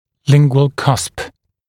[‘lɪŋgwəl kʌsp][‘лингуэл касп]язычный (лингвальный) бугор (зуба)